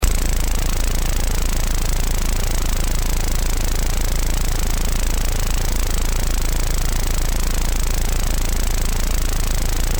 ms20-tryckluftsborr.mp3